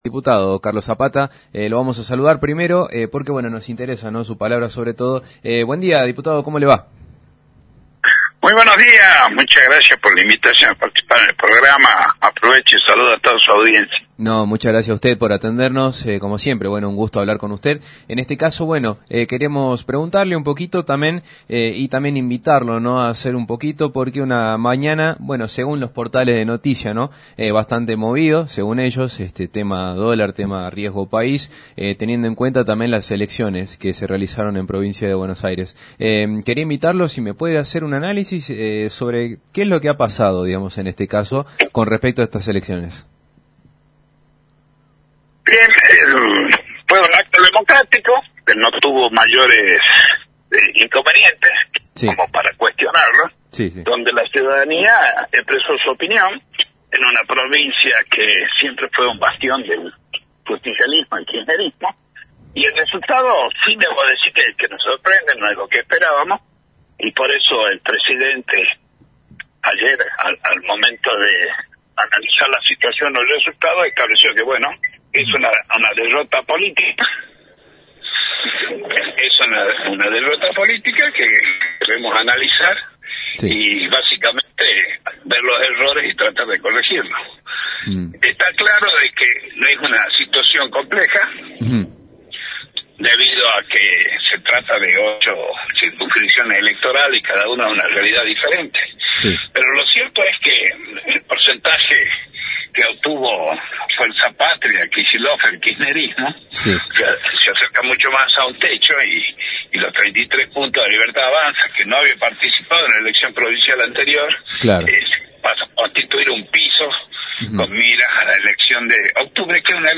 ENTREVISTA CON EL DIPUTADO NACIONAL CARLOS ZAPATA (LLA)
El diputado nacional Carlos Zapata (LLA) habló en «máxima mañana» (RADIO CADENA MÁXIMA) luego de un lunes post elecciones en PBA y las reacciones del mercado a dicha elección.